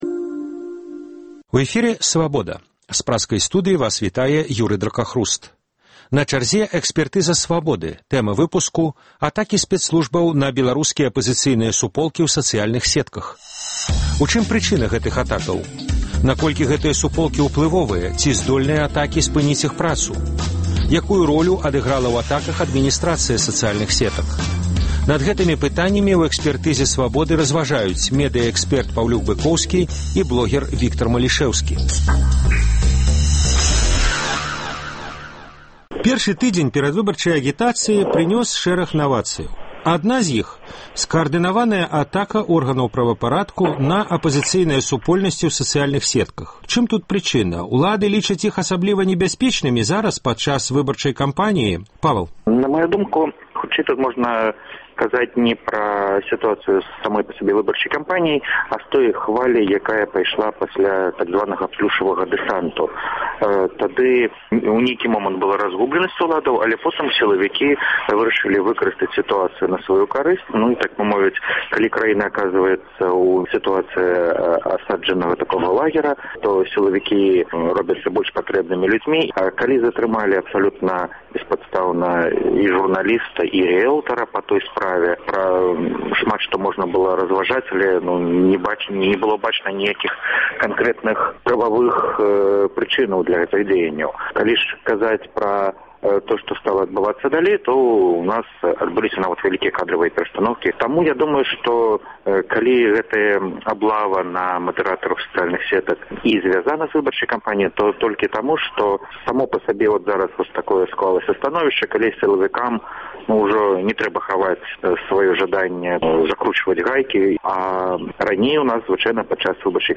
Экспрэс-аналіз бягучых падзеяў. У чым прычына атакі спэцслужбаў на беларускі апазыцыйныя суполкі ў сацыяльных сетках? Наколькі гэтыя суполкі ўплывовыя, ці здольныя атакі спыніць іх працу?